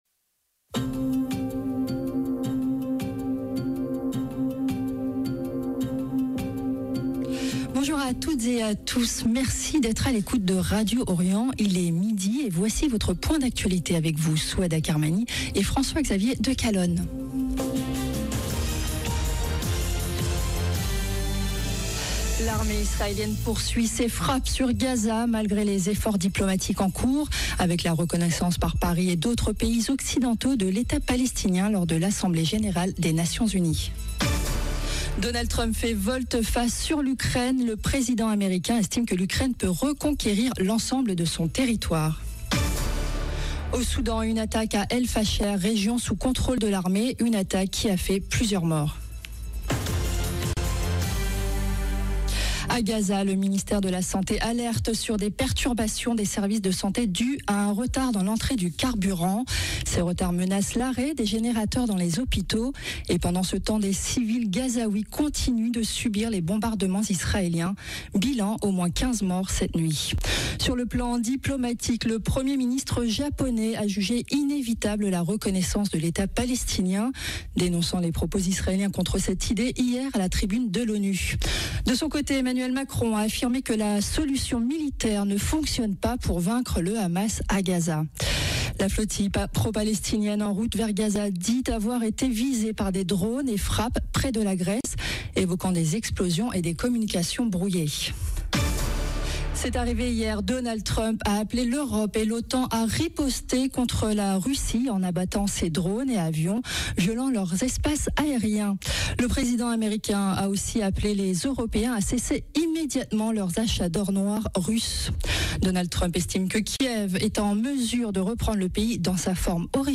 Journal de midi du 24 septembre 2025